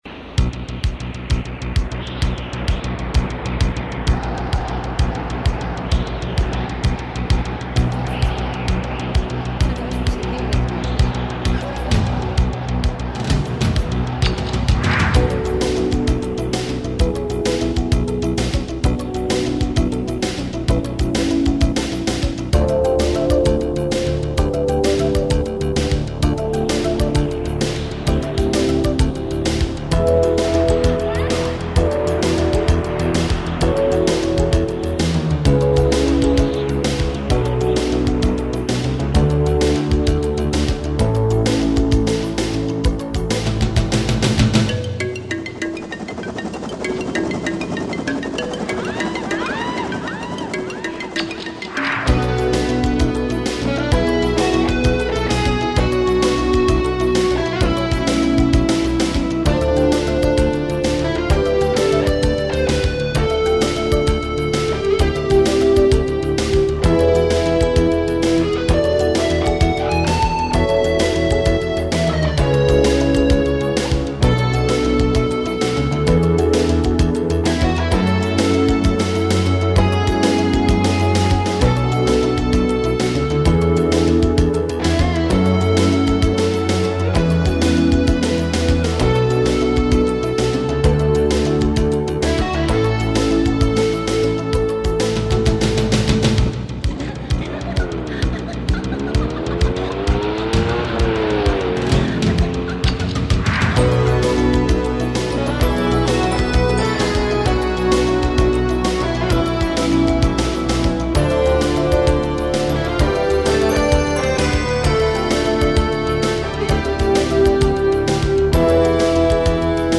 A few musical pieces composed by the artist
Mp3 file, 112 kbps, Mono. Instrumental piece contained in "Myths".